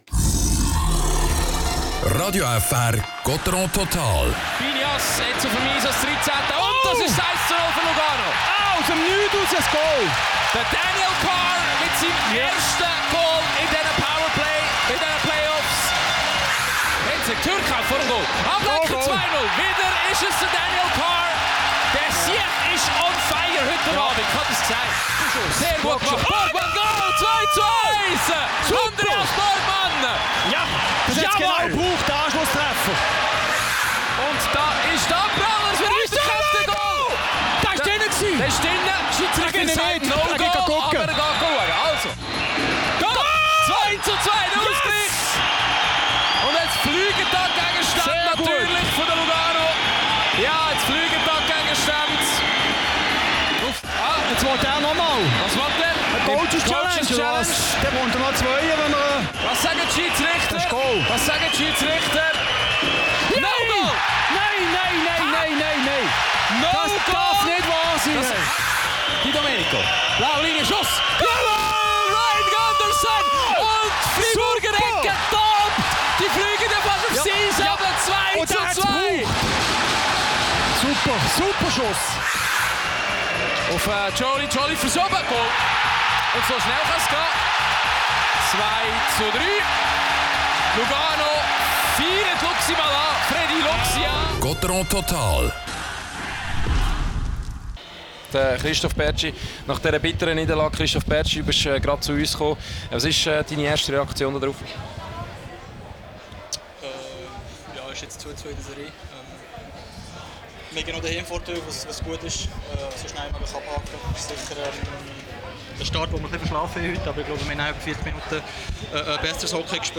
Interview mit Christoph Bertschy and Spielanalyse von